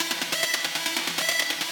Index of /musicradar/shimmer-and-sparkle-samples/140bpm
SaS_Arp04_140-C.wav